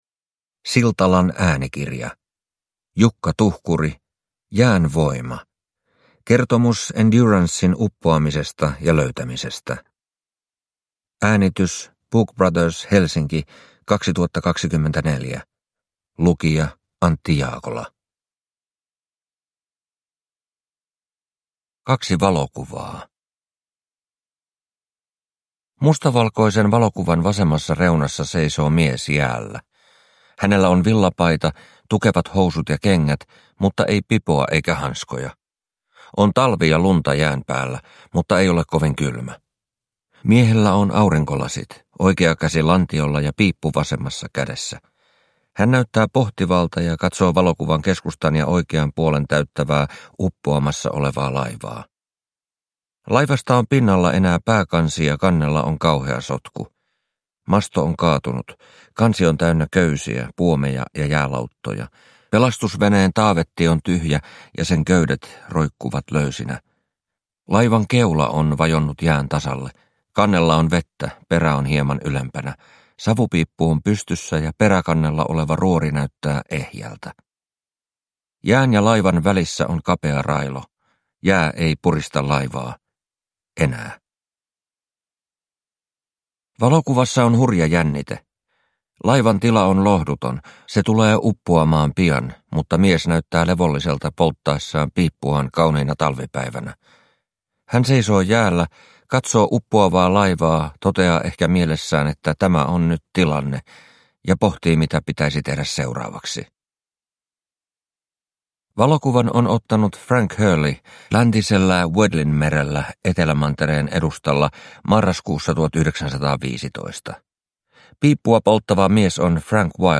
Jään voima – Ljudbok